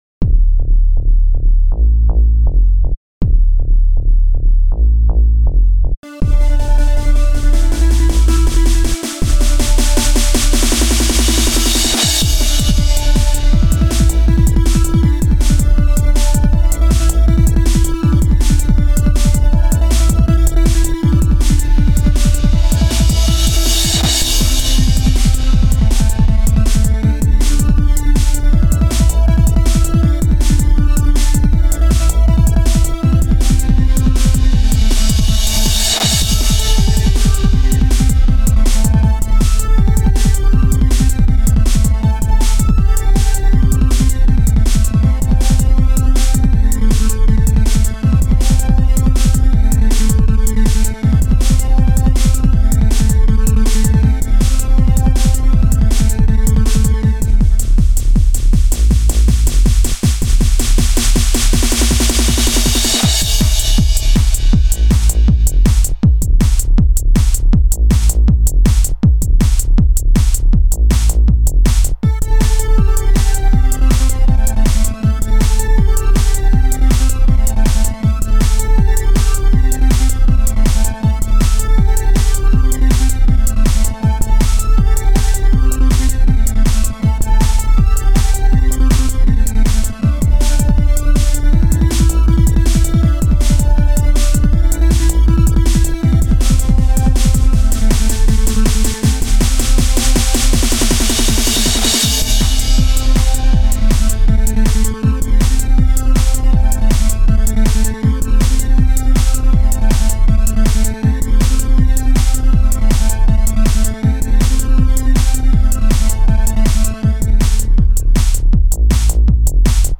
dance/electronic
Techno
Ambient
Trance